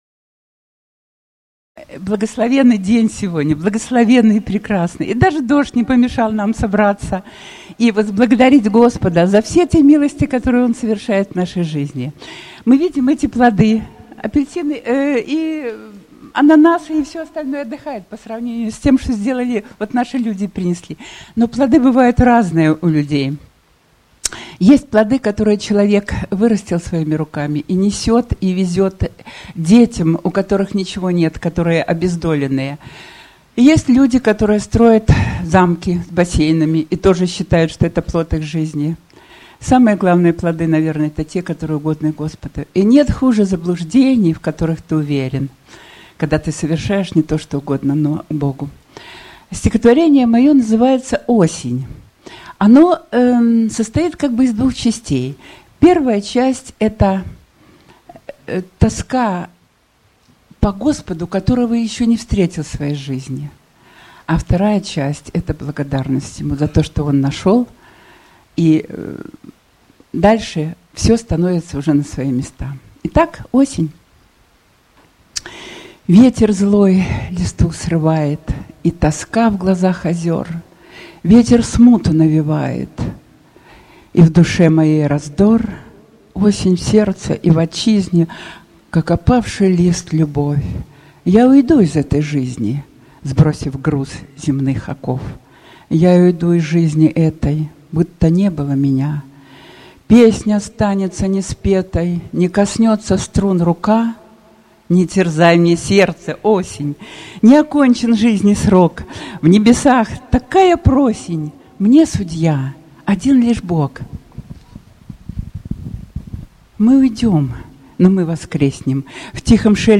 Стихотворение